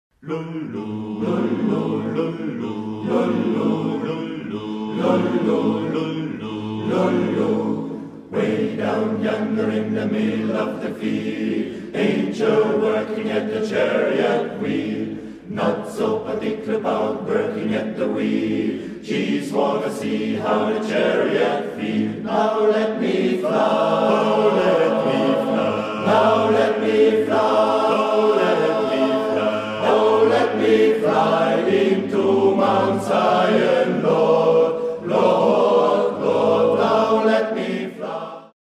• Aufgenommen im März 2005 in der Volksschule Poggersdorf
Negro Jubilee